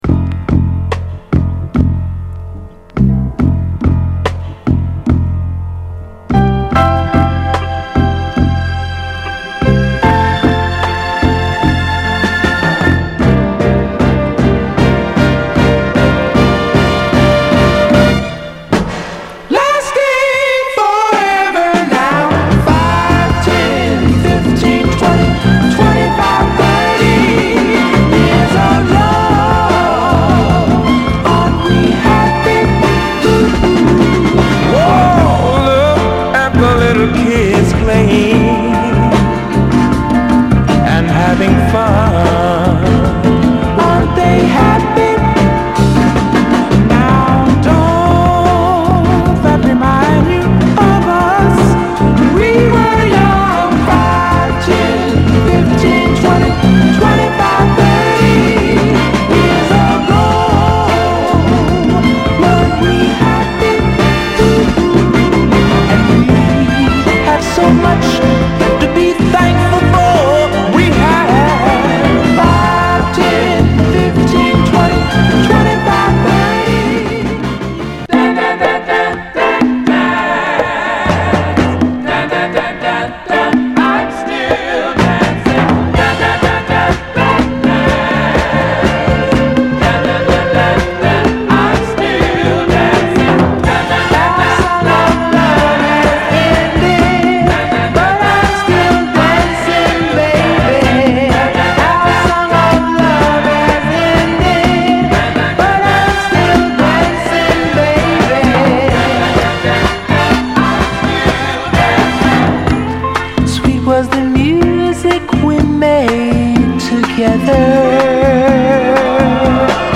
スウィート・ソウルと言えば、位のメロウ名曲ですね。
盤はエッジ中心に細かい表面スレありますが、音への影響は少なくプレイ良好です。
※試聴音源は実際にお送りする商品から録音したものです※